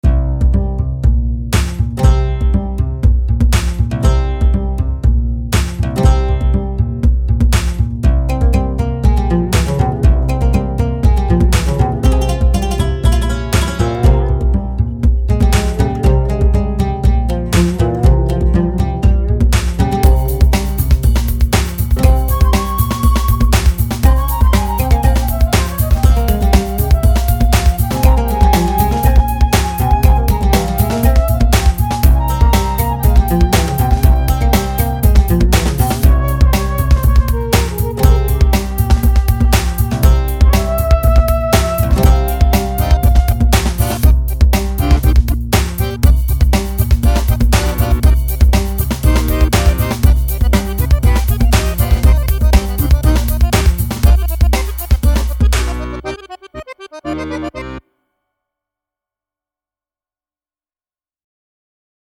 I didn’t perform any of the parts here; it’s a bunch of loops from GarageBand. The arrangement of those clips, however, is distinctly mine.